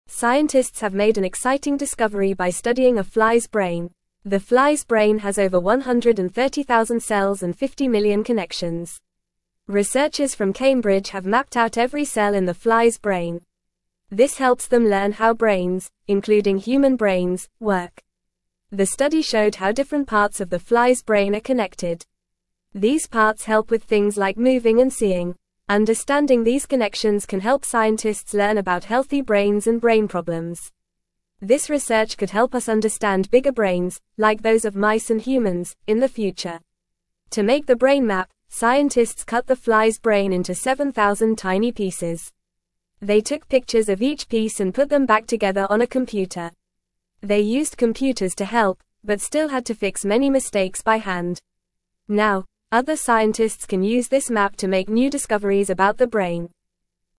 Fast
English-Newsroom-Lower-Intermediate-FAST-Reading-Scientists-study-tiny-fly-brain-to-learn-more.mp3